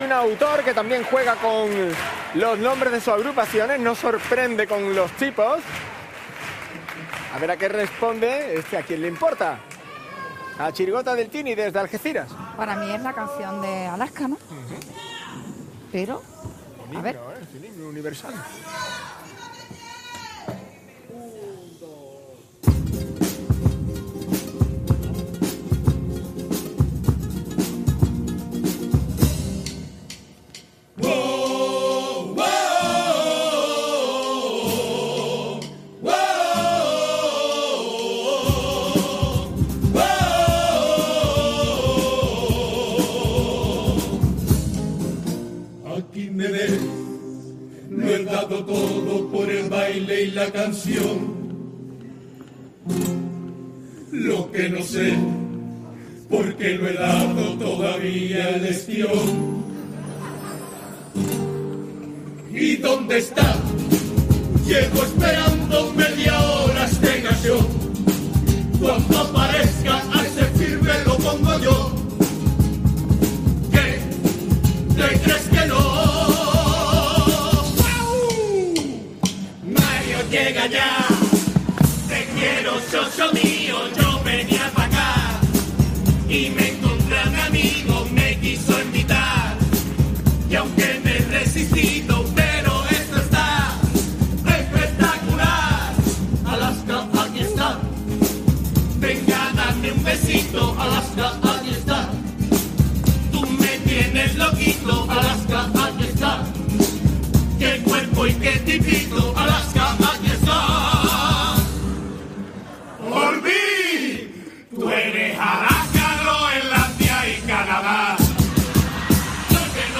en la fase preliminares del COAC Carnaval de Cádiz 2026